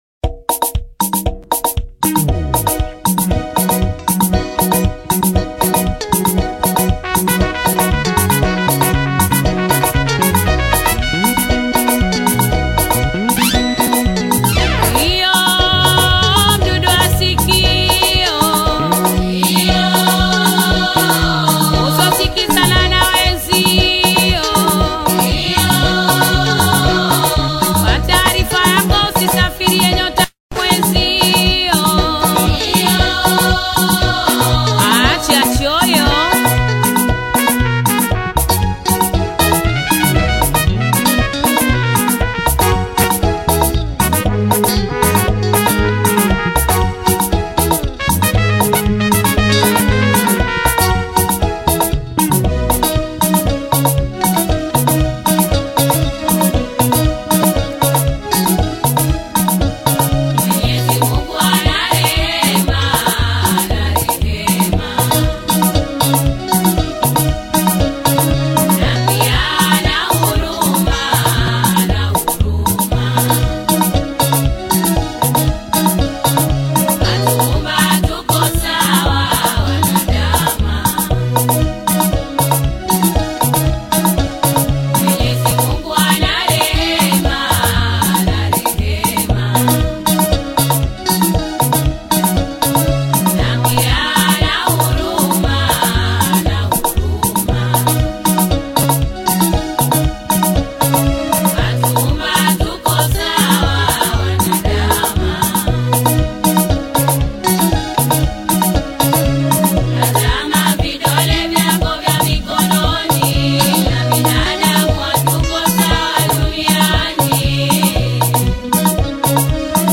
TAARABU